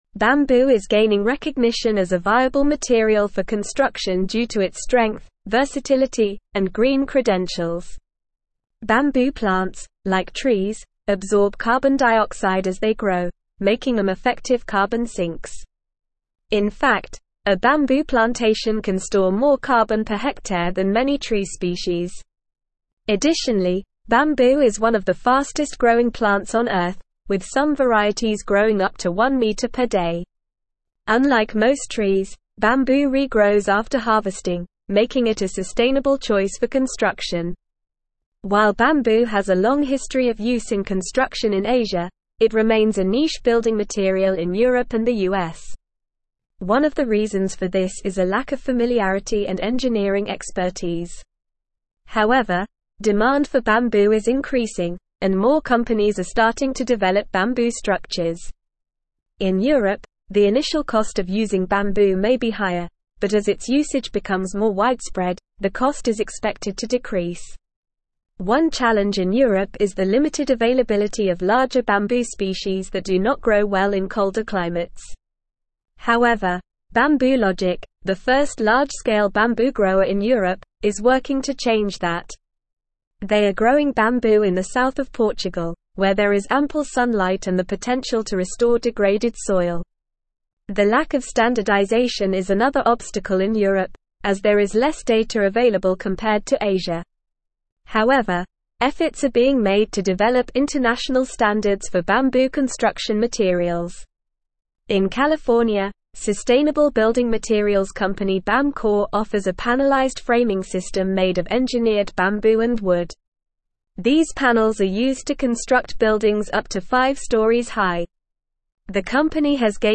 Normal
English-Newsroom-Advanced-NORMAL-Reading-Bamboo-A-Sustainable-and-Versatile-Building-Material.mp3